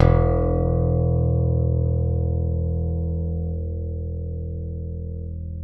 ALEM PICK E1.wav